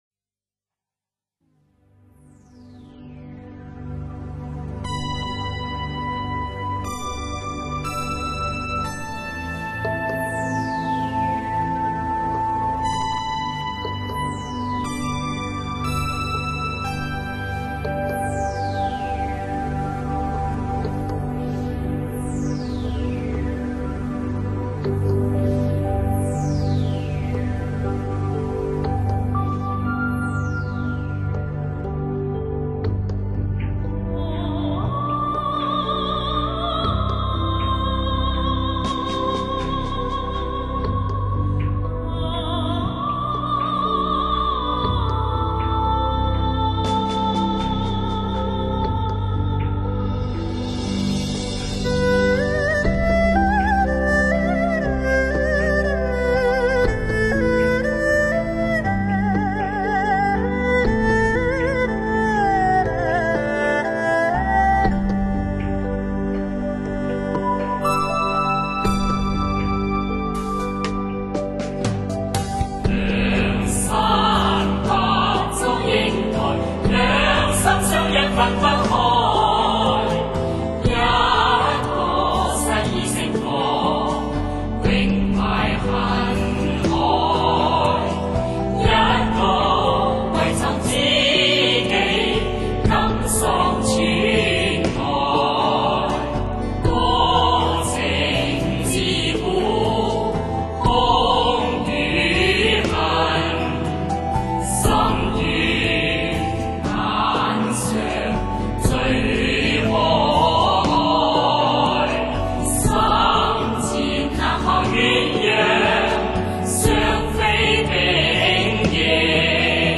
大胆的尝试各种中国乐器和现代音乐风格的融合